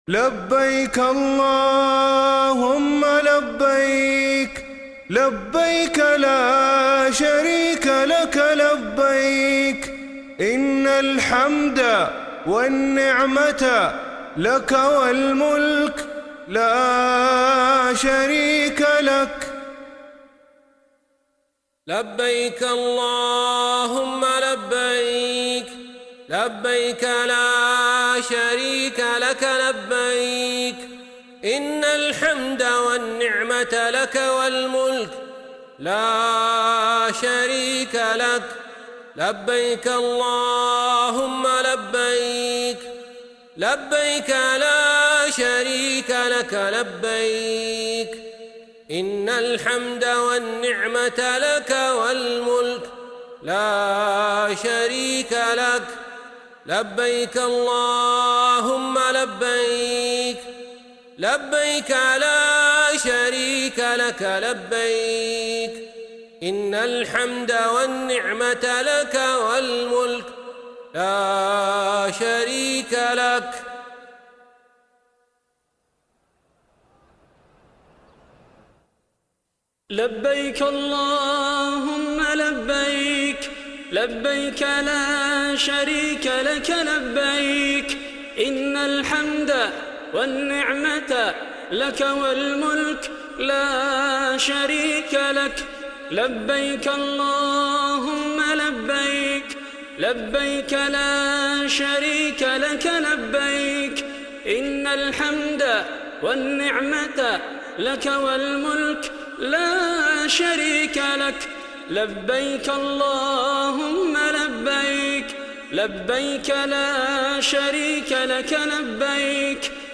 تحميل تلبية الحج ويوم عرفة لبيك اللهم لبيك mp3
كما يُتاح لكم تلبية الحجاج في أيام العشر من ذي الحجة، وفي يوم وقفة عرفة بصيغة أم بي ثري، ويُمكنكم الاستماع إلى أروع الأصوات في تلبيات الحج كما يظهر لكم في الملف الصوتي المرفق لكم في هذه الفقرة، والذي يشتمل على العديد من المقاطع المتاحة للاستماع المباشر لتلبيات الحجاج أثناء أدائهم مناسك الحج والوقوف بعرفة كما هو مرفق في الملف الصوتيّ التالي:
عودة-الطواف-بالحرم-المكي-لبيك-اللهم-لبيك-192-kbps.mp3